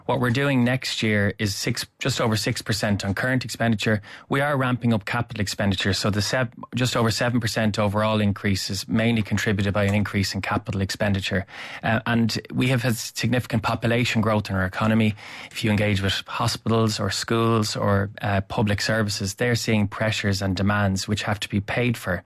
Minister Jack Chambers says the spending is needed, despite warnings from the Central Bank about sustainability.